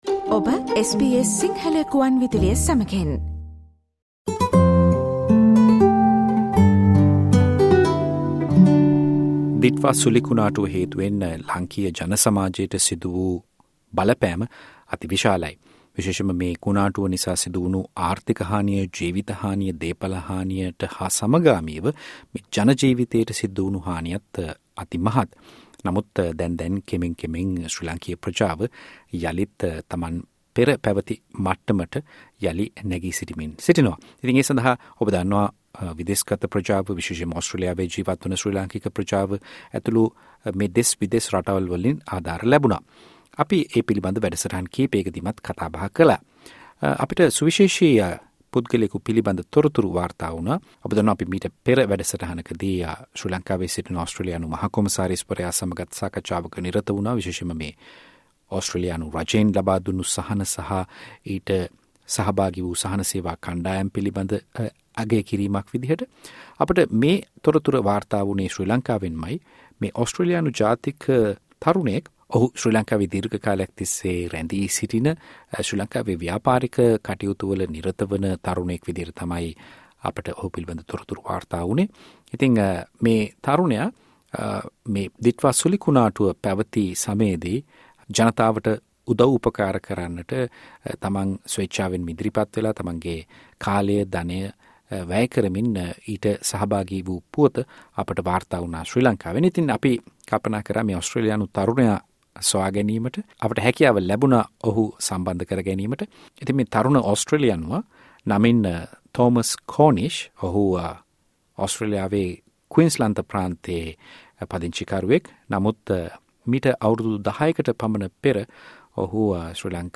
SBS සිංහල සේවය කළ කතාබහක්